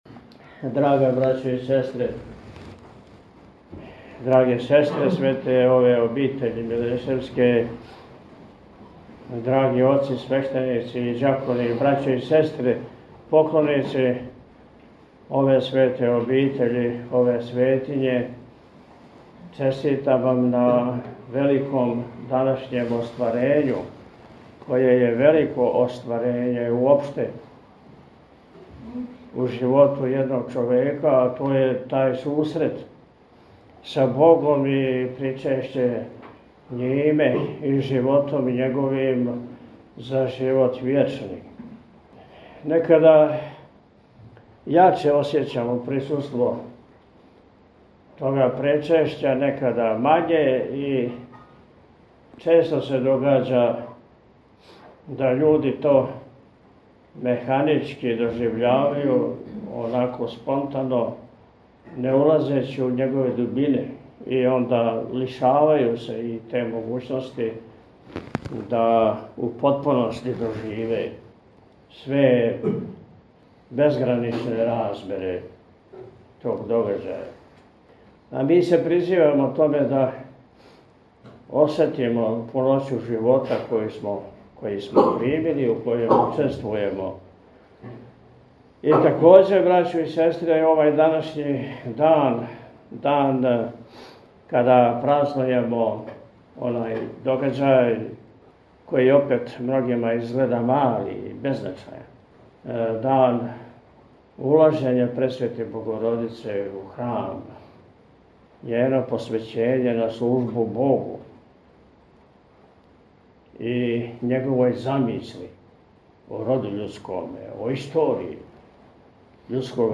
Његово Високопреосвештенство Архиепископ и Митрополит милешевски г. Атанасије служио је у среду 4. децембра 2024. године, на празник Ваведења Пресвете Богородице, Свету архијерејску Литургију у параклису Свете Касијане манастира Милешеве.
Честитајући сабранима учешће у Евхаристијском сабрању, Високопреосвећени је у пастирској беседи, између осталог, рекао: – Некада јаче осећамо присуство Причешћа, некада мање, и често се догађа да људи то механички доживљавају, спонтано, не улазећи у његове дубине, и тиме се лишавају те могућности да у потпуности доживе све безграничне размере тог догађаја. А ми смо призвани да осетимо пуноћу живота који смо примили, у коме учествујемо.